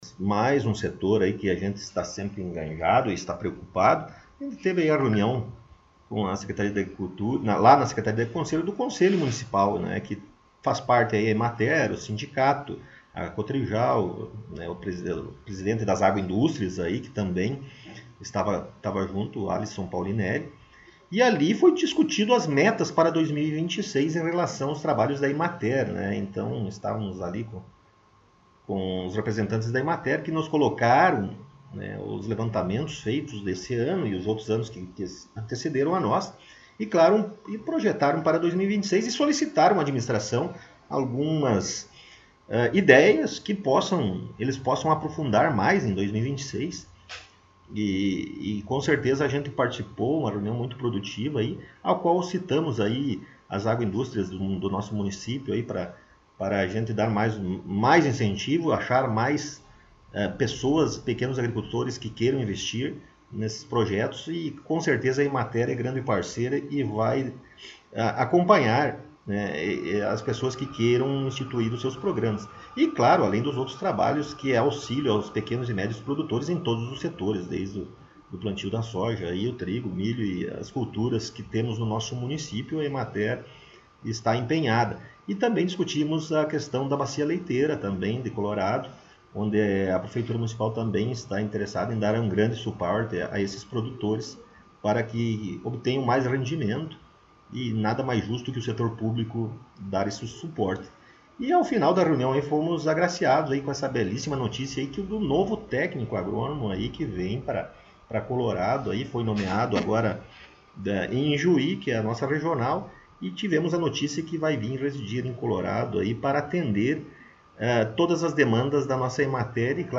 Rodrigo Sartori: Prefeito Municipal concedeu entrevista